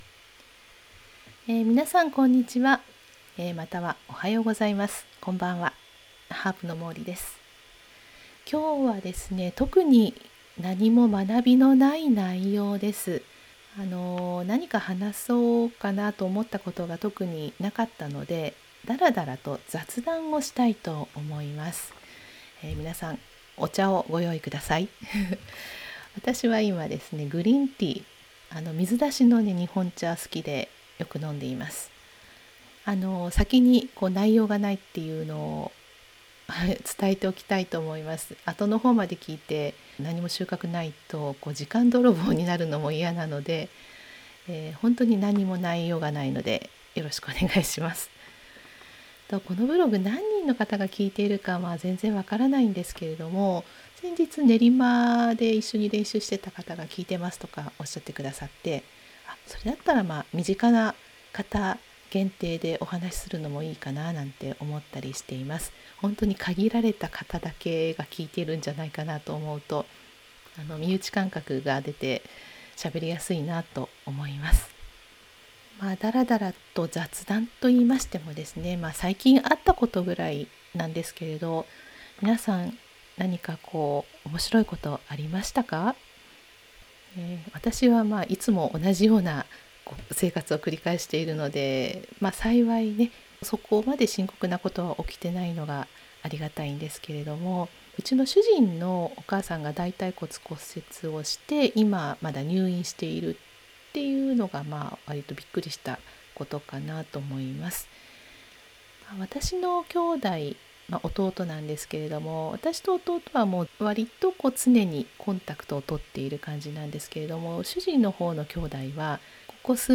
（音声ブログ）ただの雑談